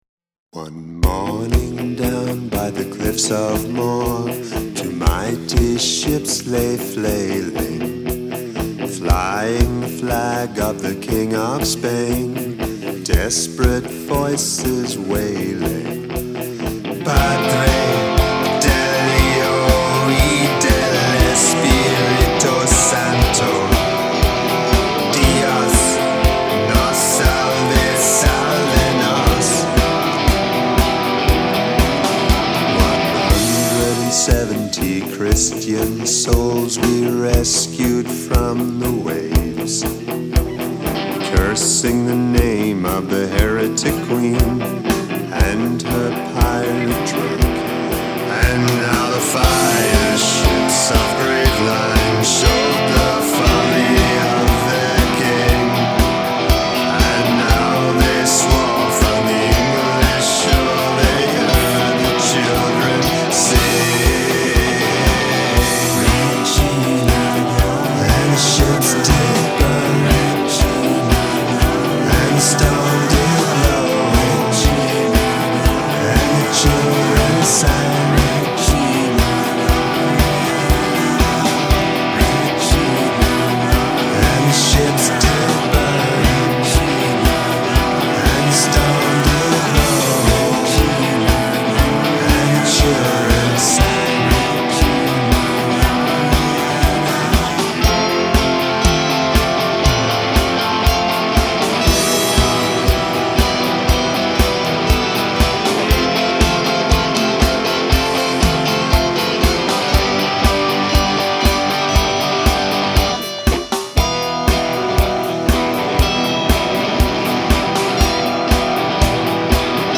Dramatic Change in Tempo